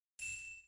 Soft-Notifications - Bell - HighDing
Bell ding Notification SFX Soft UI sound effect free sound royalty free Sound Effects